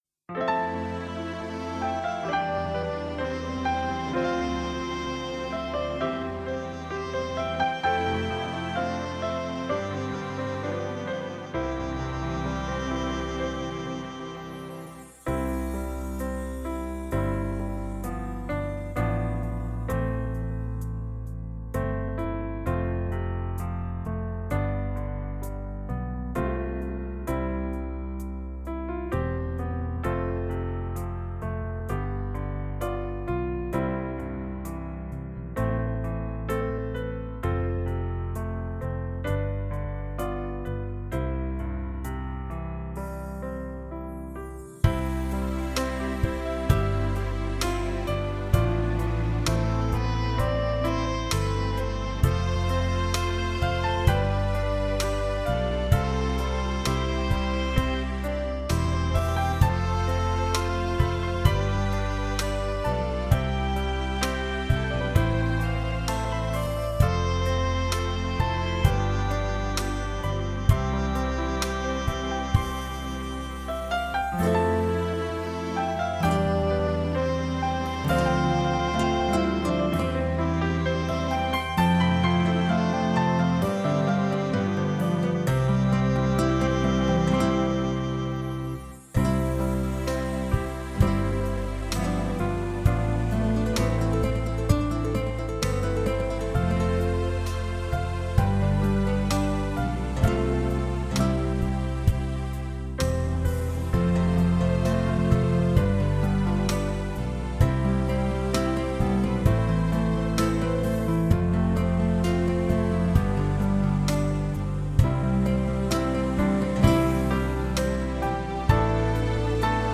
Orkesttrack Santo v.a. 15 sec.
Kw_Santo_65bpm1.mp3